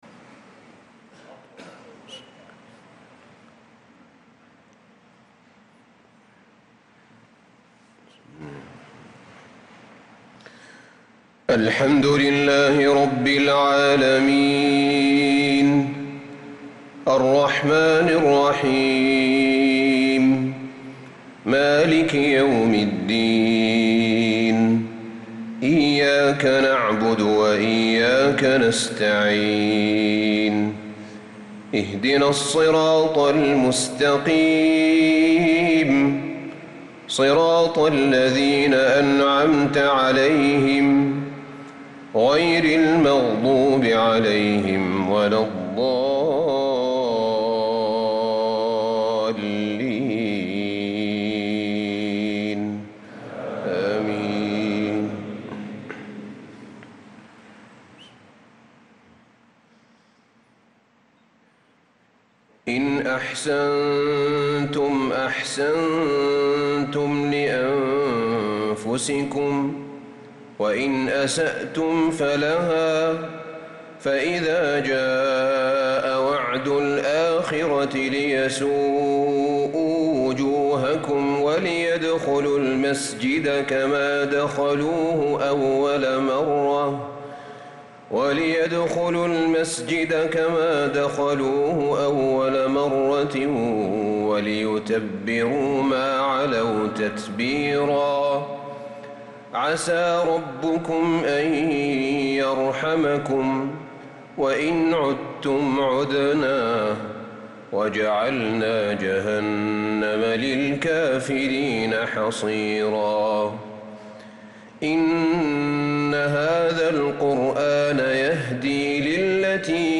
صلاة المغرب للقارئ أحمد بن طالب حميد 3 ذو الحجة 1445 هـ
تِلَاوَات الْحَرَمَيْن .